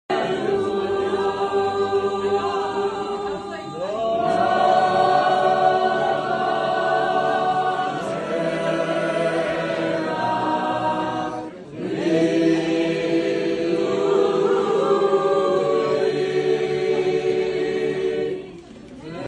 medieval chant